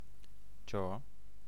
Ääntäminen
Ääntäminen letter name: IPA: /tʃo/ phoneme: IPA: /tʃ/ Haettu sana löytyi näillä lähdekielillä: esperanto Käännöksiä ei löytynyt valitulle kohdekielelle.